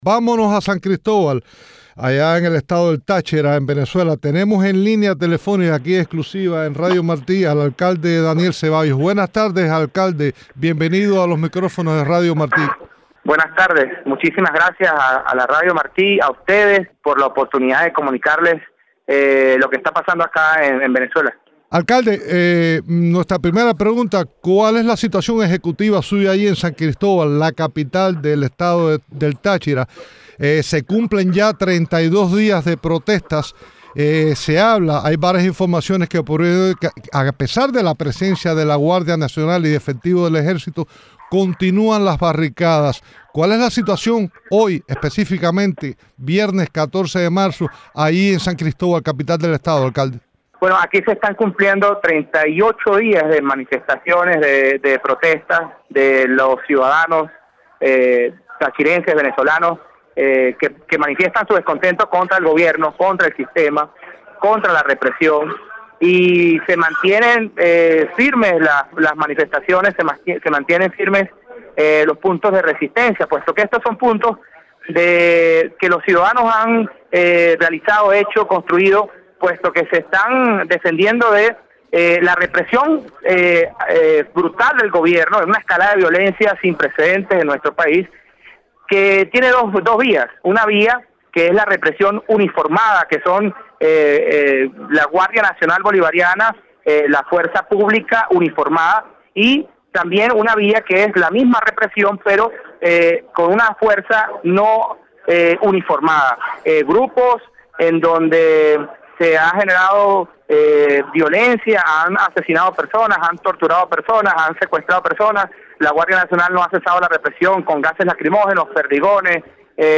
Declaraciones del alcalde de Táchira, Daniel Ceballos en "Cuba al día"